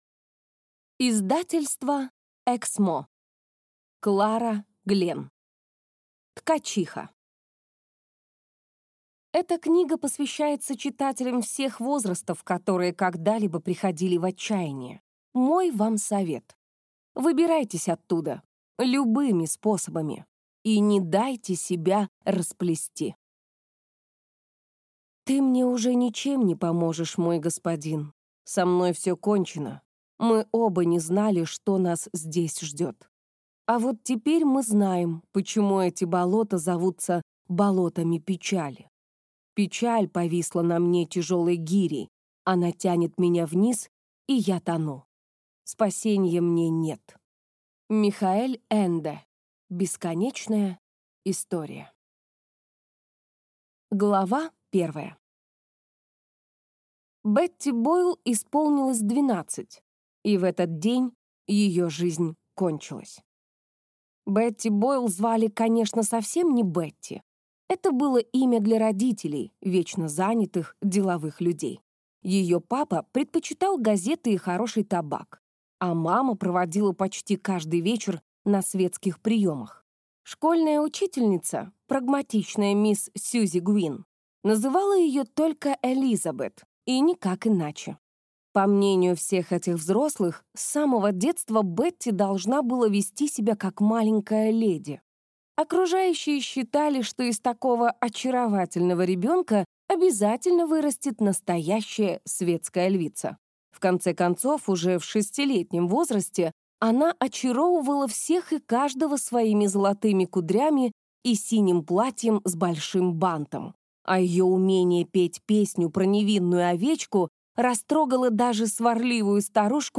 Аудиокнига Ткачиха | Библиотека аудиокниг
Прослушать и бесплатно скачать фрагмент аудиокниги